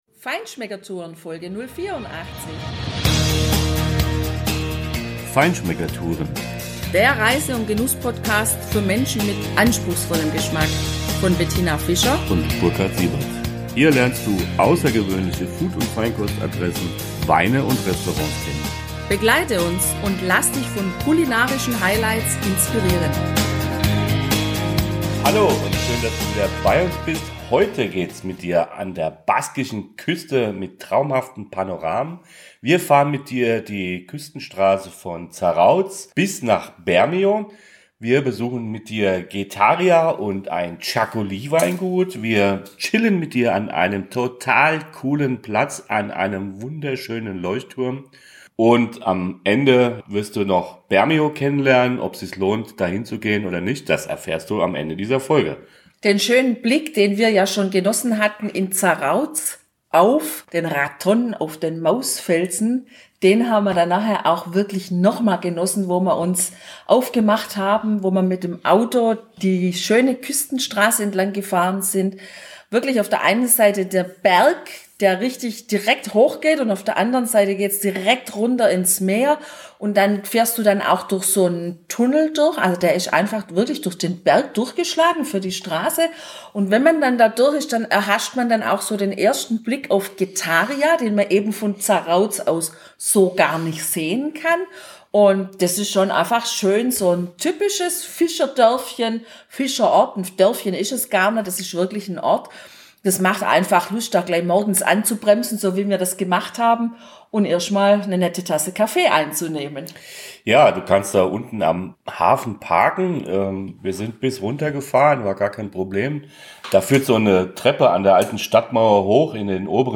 Traumhafte Panoramen an der baskischen Küste kannst du heute erleben, wenn wir mit dir die Küstenstraßen von Getaria bis nach Bermeo fahren. Wir gehen mit dir durch die Stadt des Mausfelsens und besuchen das Herz der Txakoli-Herstellung. Neben einer Live-Txakoli-Verkostung hörst du, was ein Flysch ist.